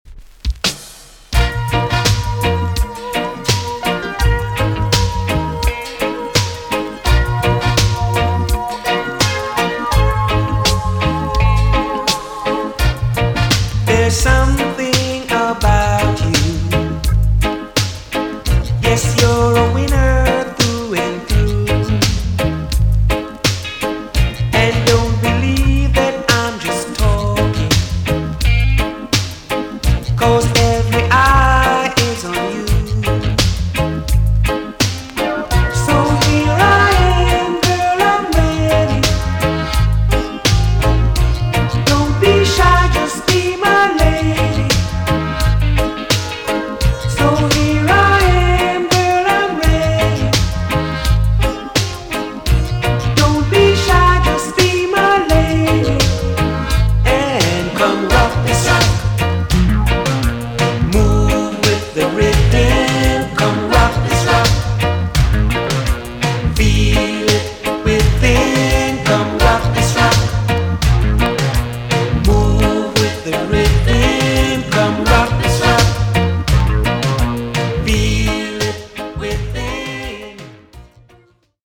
TOP >80'S 90'S DANCEHALL
EX- 音はキレイです。
NICE VOCAL TUNE!!